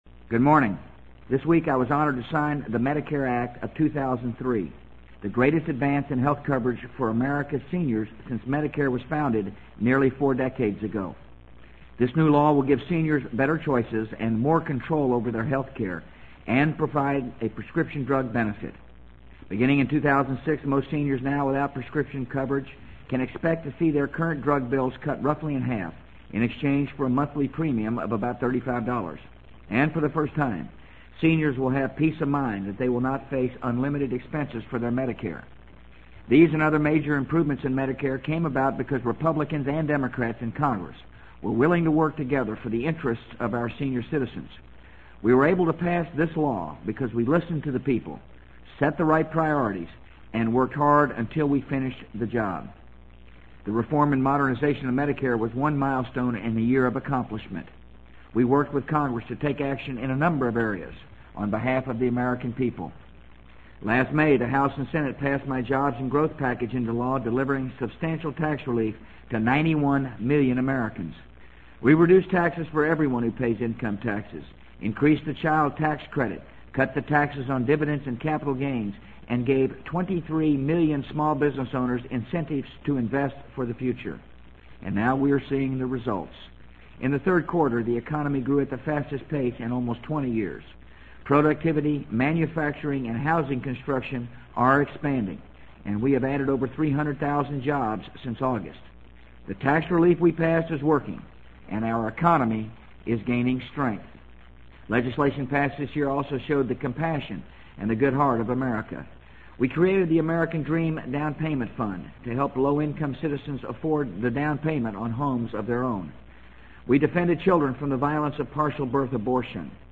【美国总统George W. Bush电台演讲】2003-12-13 听力文件下载—在线英语听力室